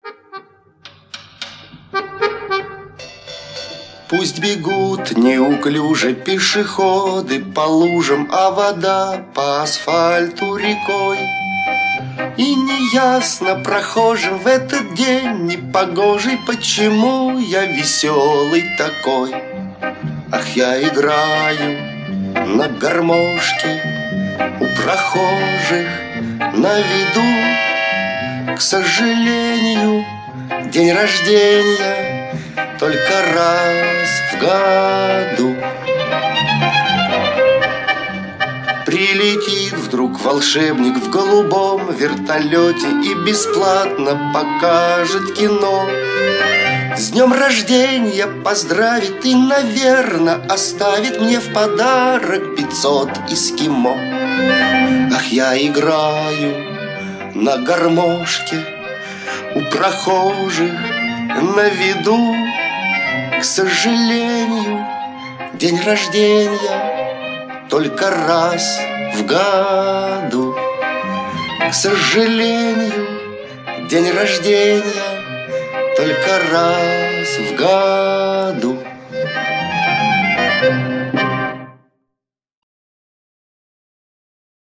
незатейливой, но такой понятной мелодией